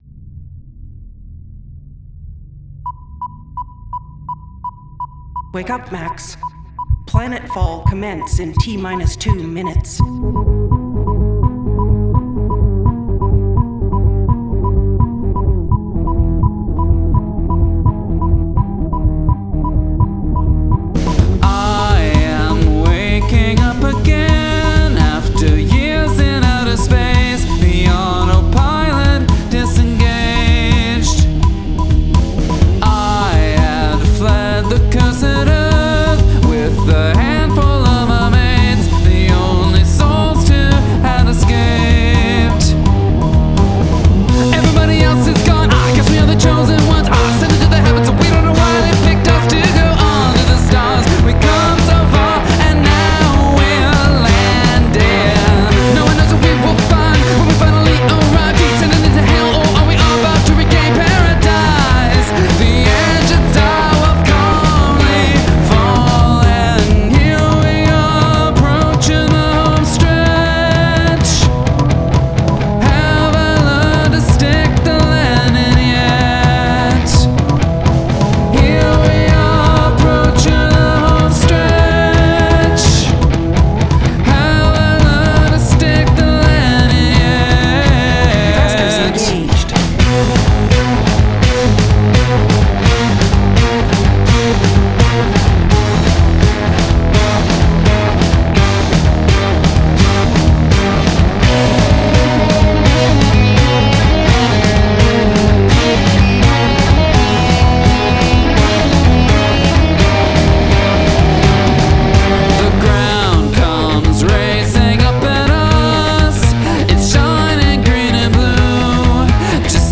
Polyrhythm